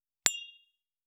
272,ショットグラス乾杯,乾杯,アルコール,バー,お洒落,モダン,カクテルグラス,ショットグラス,おちょこ,テキーラ,シャンパングラス,カチン,チン,
コップ